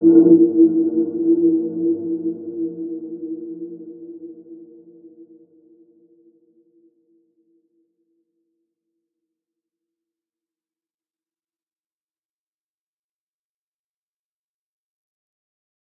Dark-Soft-Impact-E4-p.wav